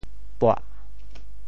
钵（鉢） Radical and Phonetic Radical 钅 Total Number of Strokes 10 Number of Strokes 5 Mandarin Reading bō TeoChew Phonetic TeoThew buah4 白 Chinese Definitions 钵 <名> (形声。